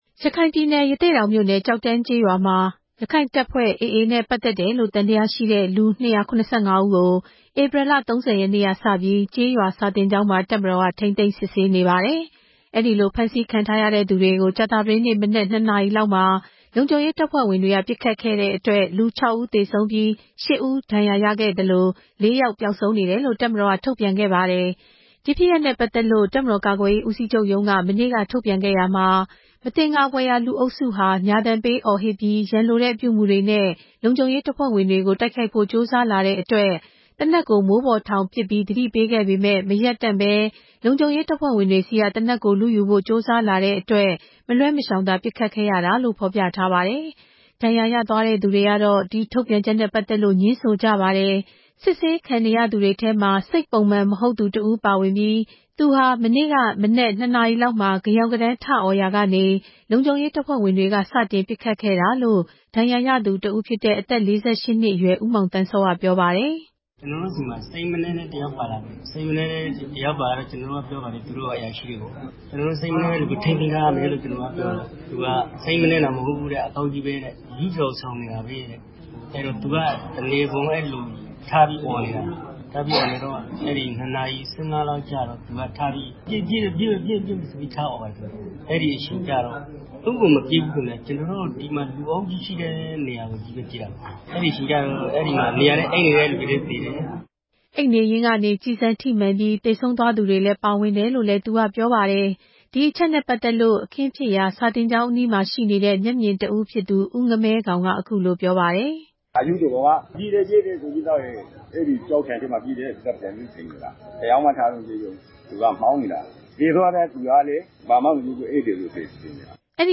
ကျောက်တန်းရွာ ပစ်ခတ်မှု မျက်မြင်တွေရဲ့ ပြောဆိုချက်